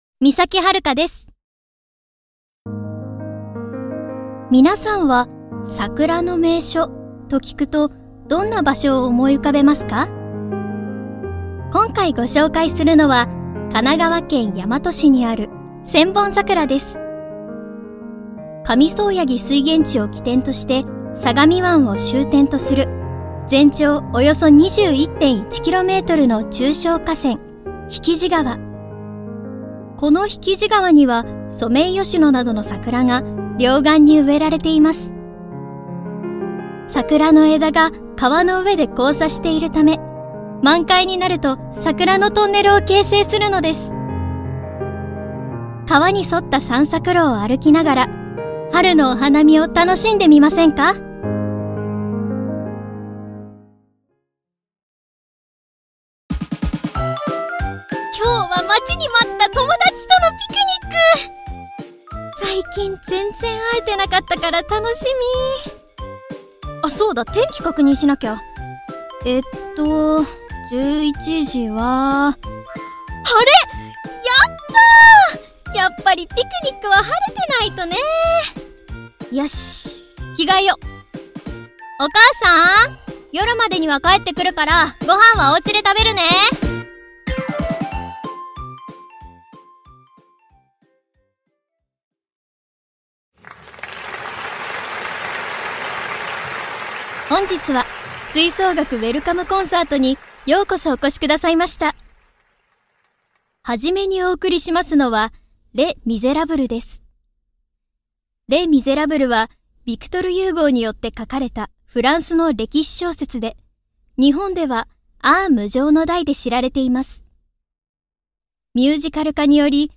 上品な純粋〜庶民的な明るい声